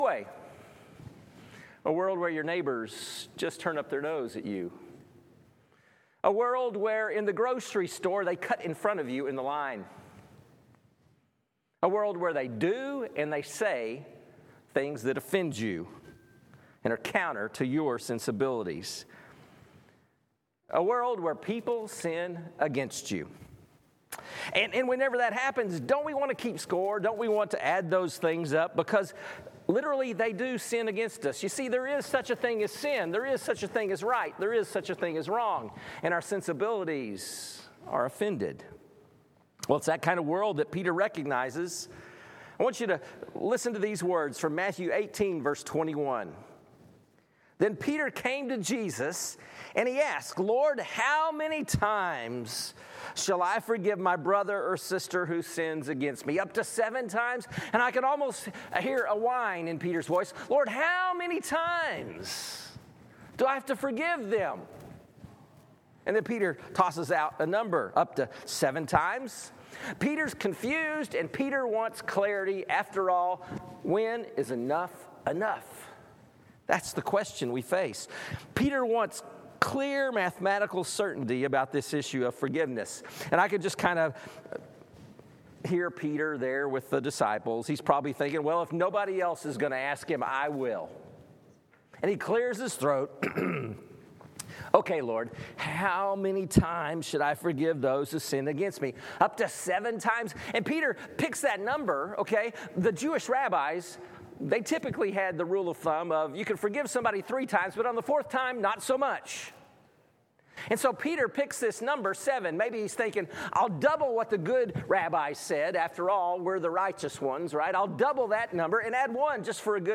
Weekly Sermon Audio “Do the Math!”